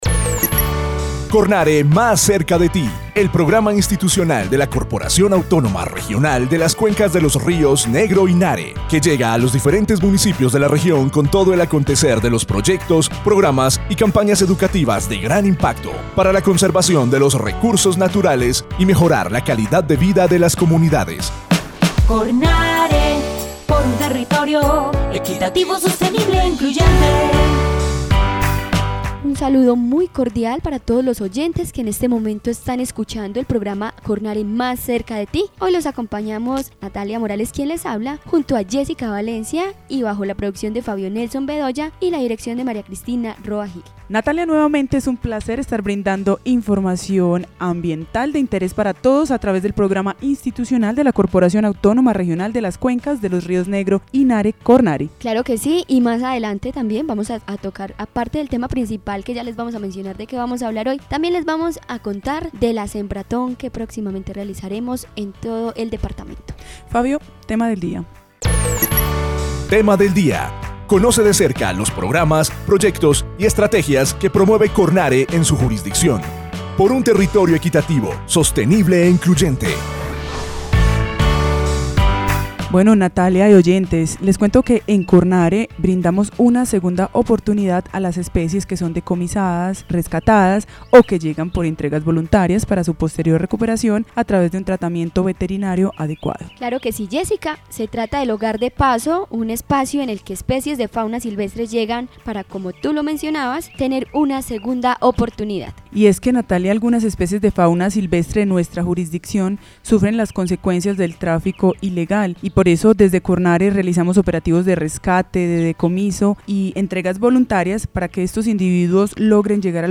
Programa de radio